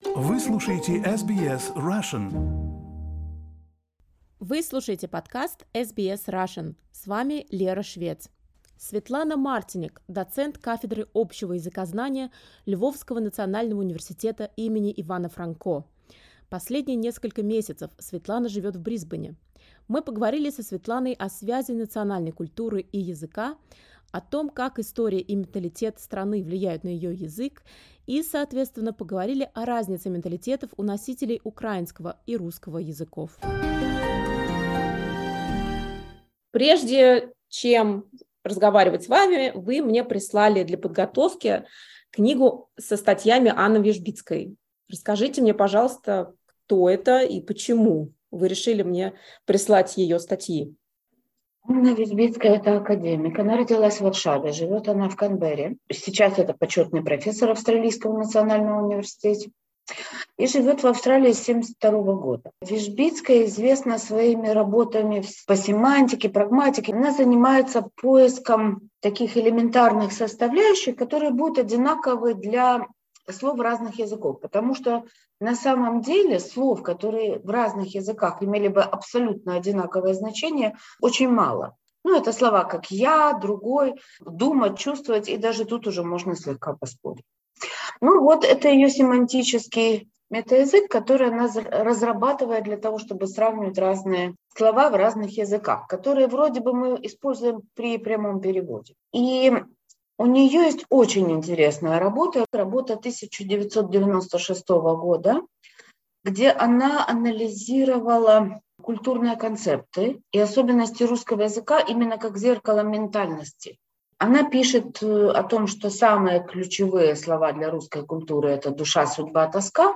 What do the Ukrainian and Russian languages tell us about the differences in the mentalities of the two nations? Interview